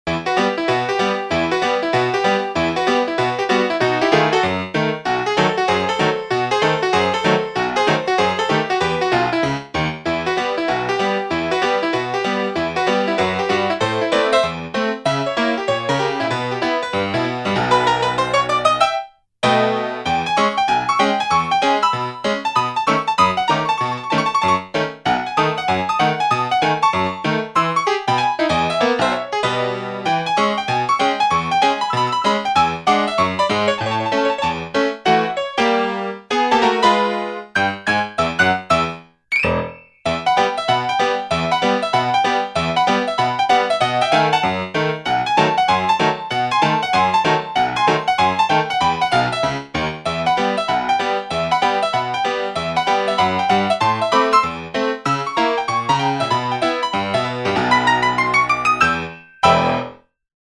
• Качество: высокое
Такую мелодию можно исполнить на фортепиано